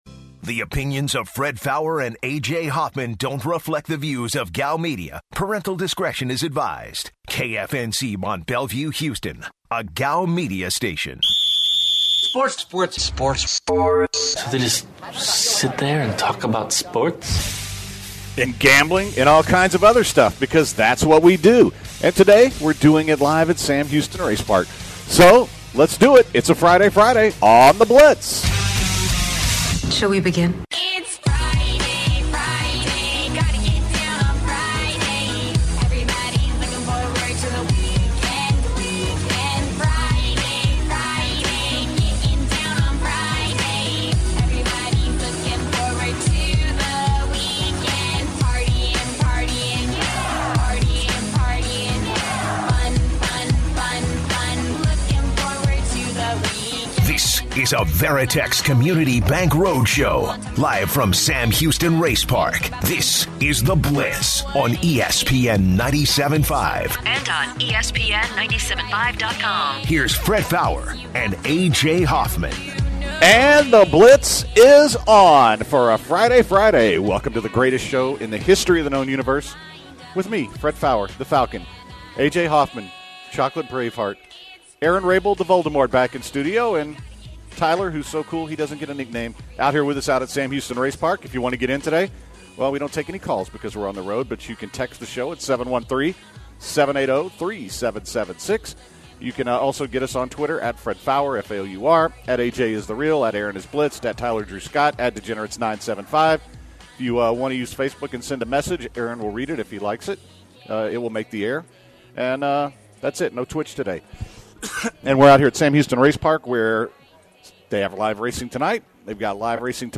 The guys are on location today at Sam Houston race park